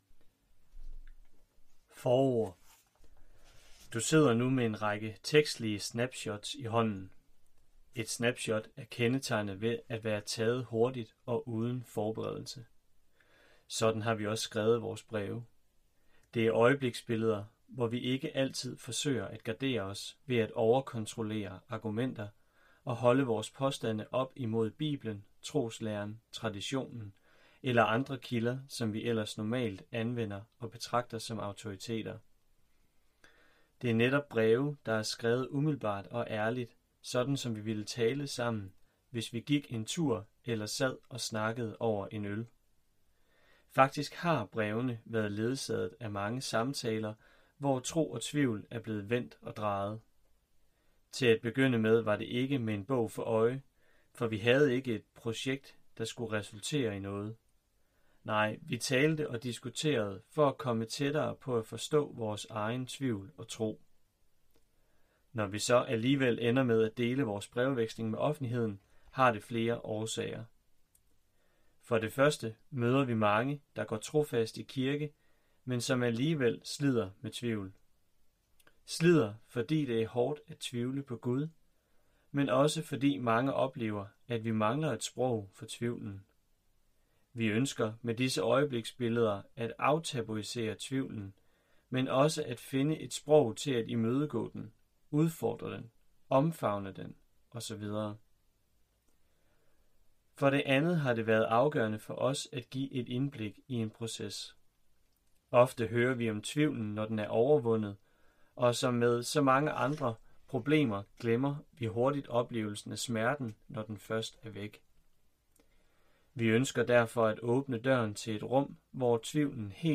Hør et uddrag af Øjebliksbilleder fra tvivlens landskab Øjebliksbilleder fra tvivlens landskab Breve om tro og tvivl Format MP3 Forfatter Michael Agerbo Mørch og Andreas Kammersgaard Ipsen Bog Lydbog 74,95 kr.